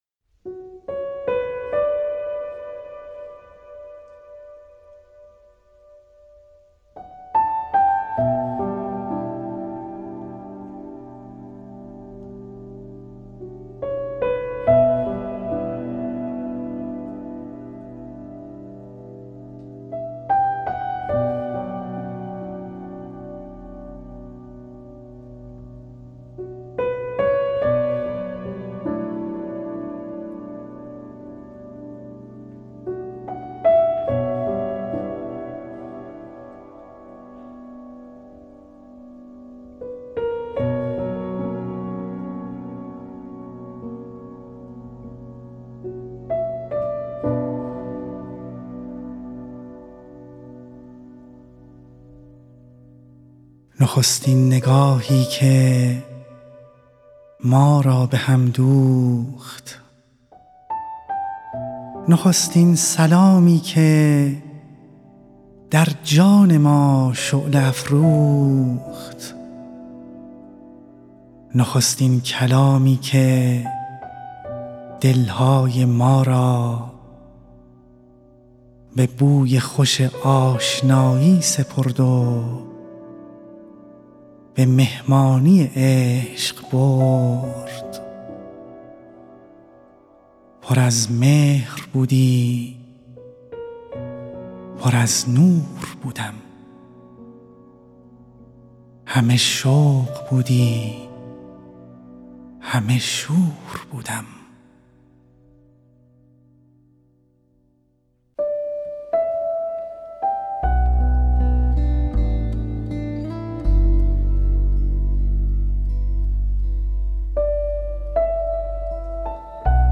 به گزارش خبرنگار فرهنگی خبرگزاری تسنیم، «پرواز خیال» مجموعه آثارِ صوتی است که با هدفِ مروری بر سروده‌های شعرای معاصر ایران و جهان تهیه و ضبط شده است. در این مجموعه برخی از سروده‌های مهم ادبیات ایران و جهان انتخاب و با همراهی موسیقی خوانده شده‌اند.